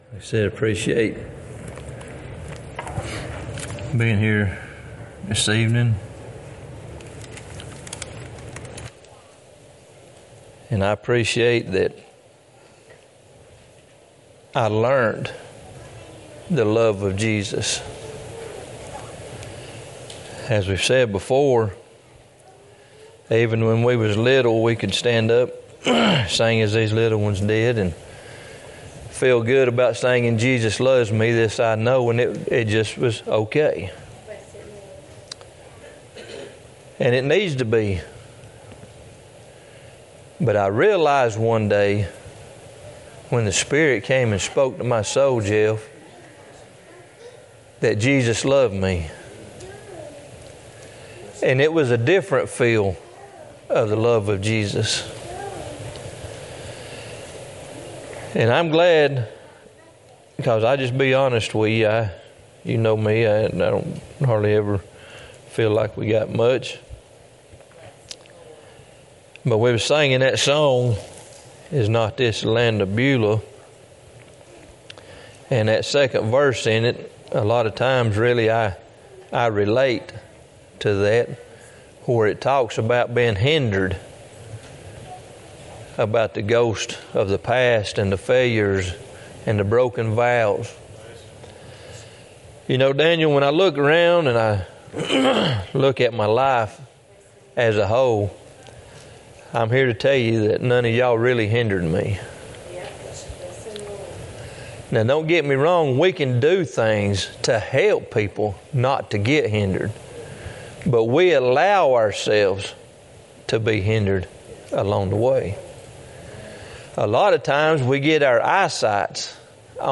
Isaiah 5:13-14 Jeremiah 2:13-14 2 Chronicles 7:14 Service Type: Wednesday night Topics